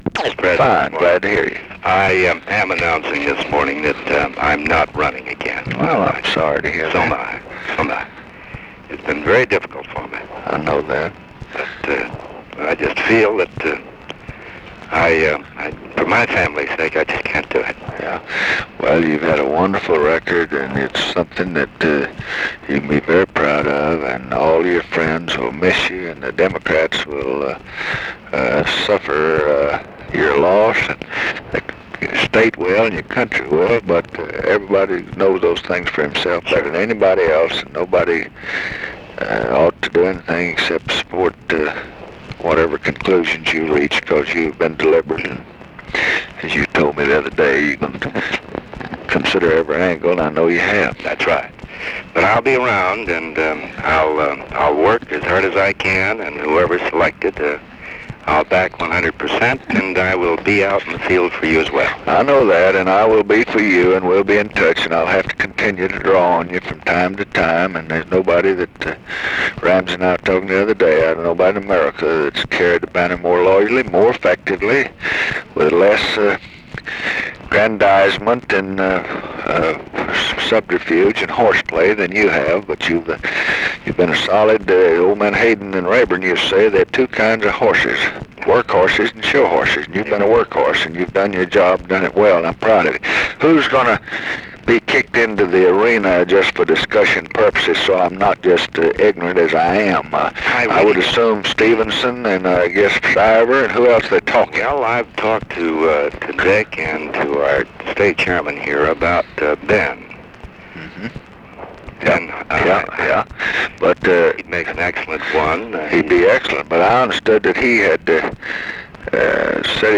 Conversation with OTTO KERNER, February 7, 1968
Secret White House Tapes